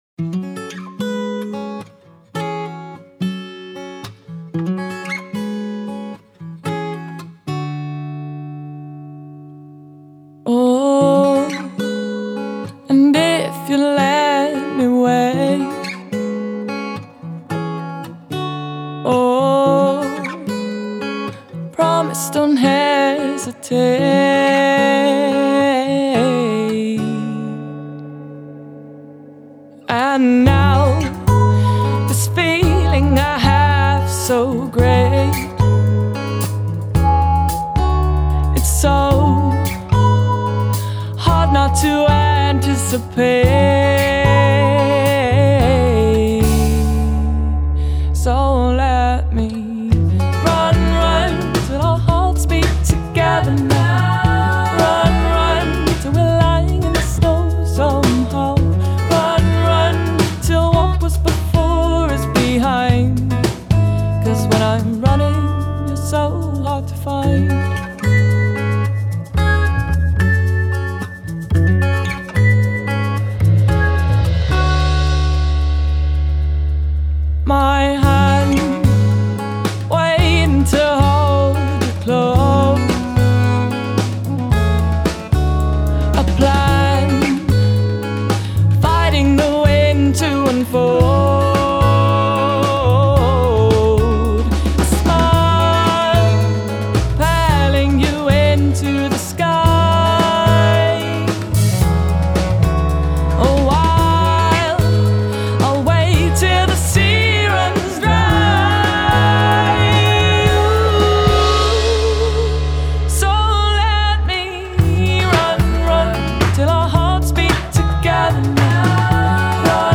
smartly expressed, acoustic-based
dusky alto